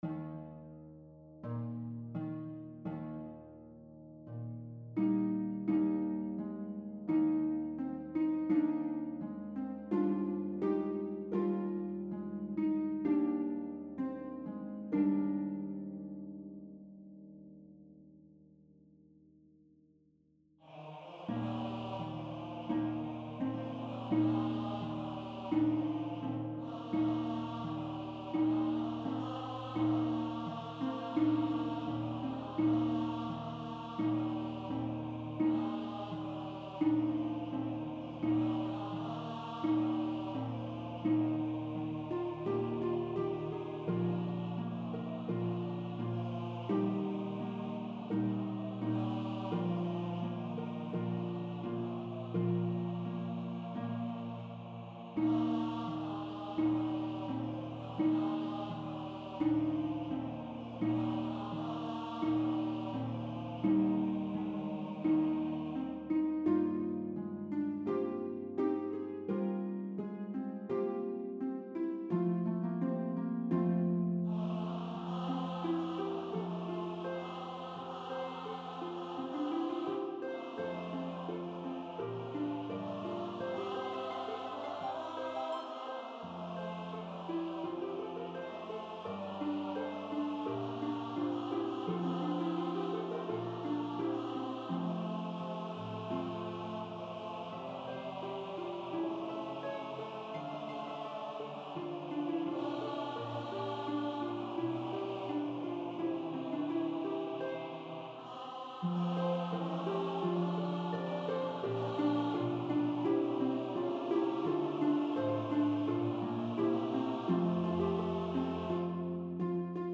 SATB, Harfe und Bläserquartett